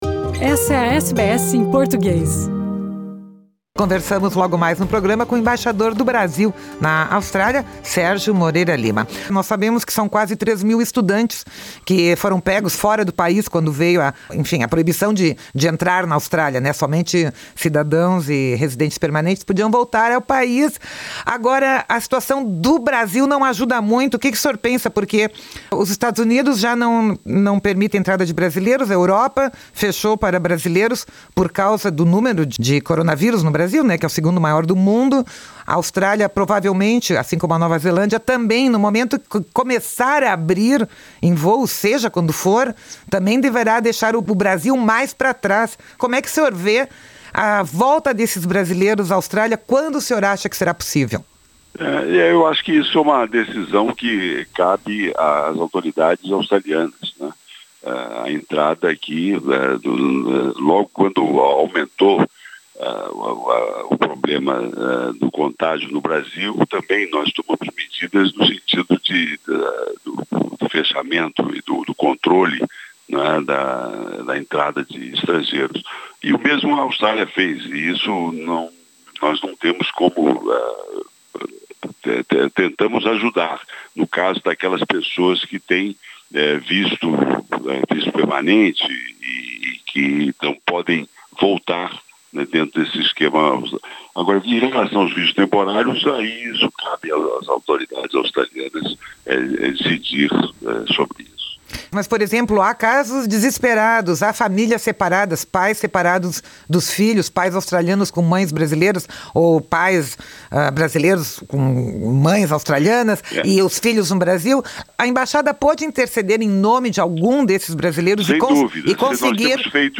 Conversamos com o embaixador brasileiro em Camberra, Sérgio Moreira Lima, sobre a situação de quem não consegue voltar à Austrália em meio à pandemia e como a representação brasileira poderia ajudá-los. Ele diz que tudo agora está na mão das autoridades australianas, mas que a embaixada tem intercedido em alguns casos.